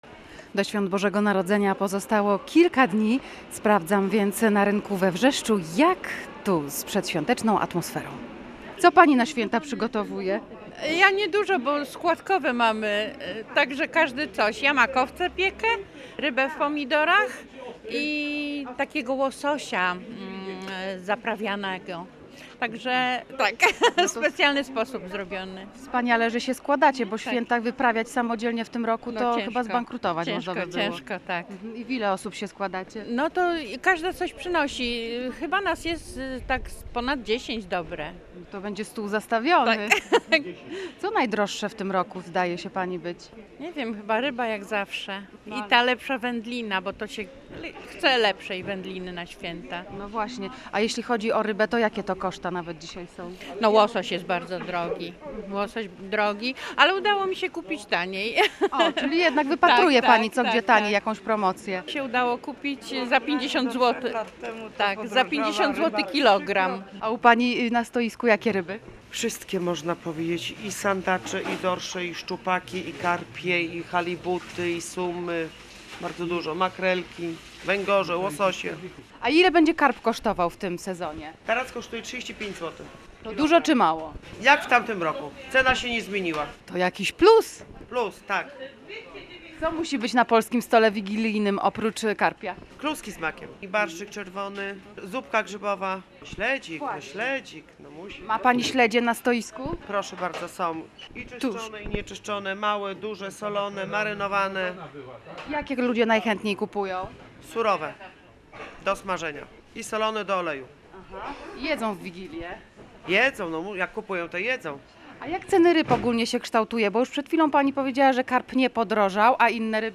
W audycji „Gotuj się na weekend” postanowiliśmy odwiedzić targowisko w Gdańsku-Wrzeszczu.